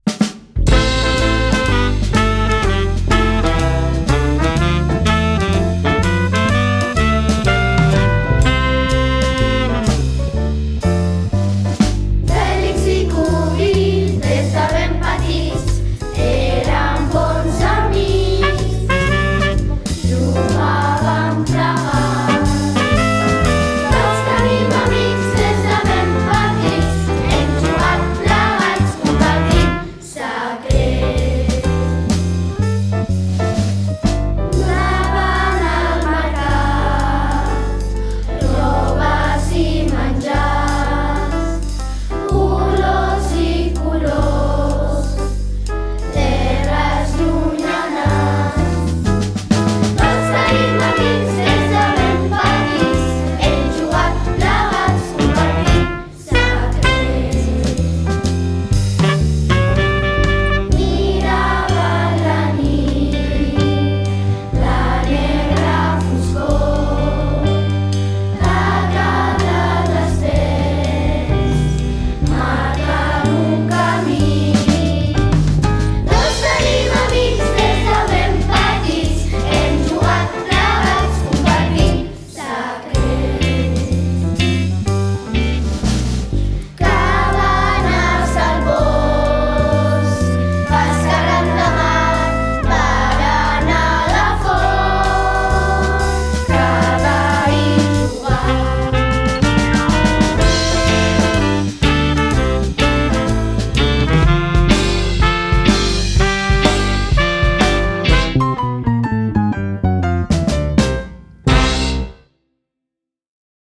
Versió cantada: